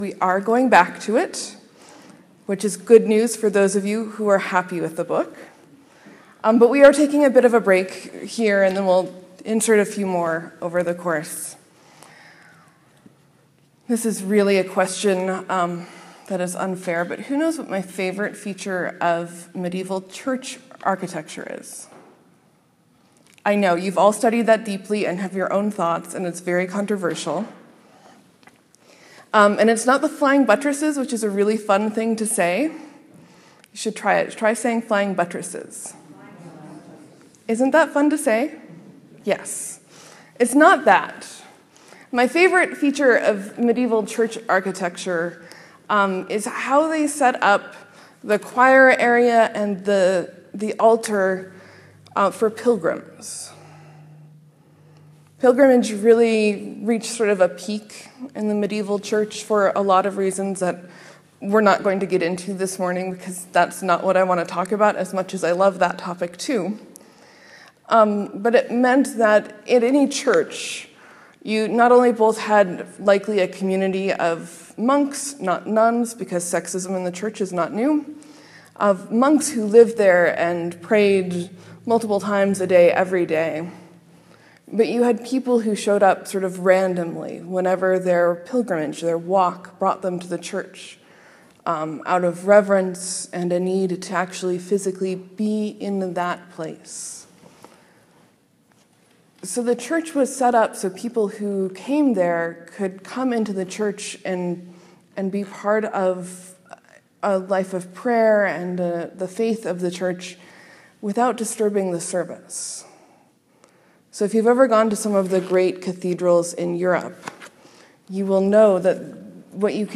Sermon: Jesus makes a sweeping statement.